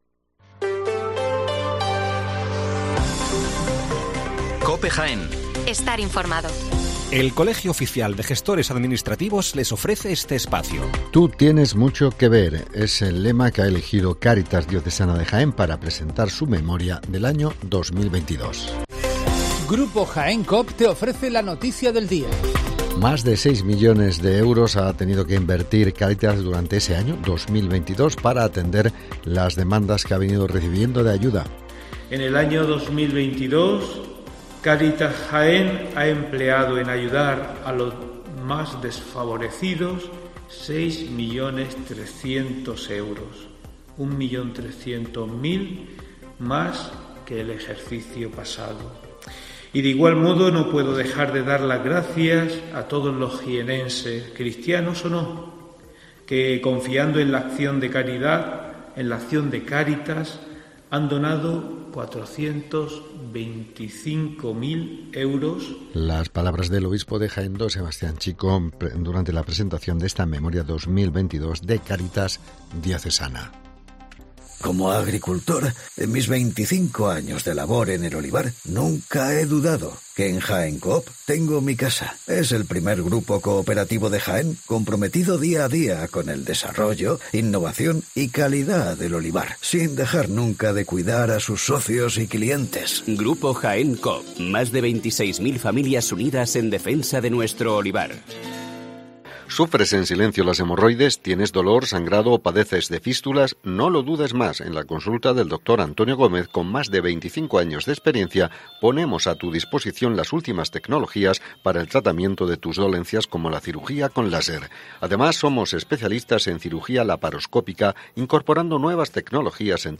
Las noticias locales de las 7'55 horas del 7 de junio de 2023